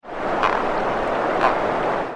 Release Calls
Sounds  This is a 2 second recording of two short release calls of a male frog as it was grabbed across the back. It was recorded in the air. Flowing water can be heard in the background.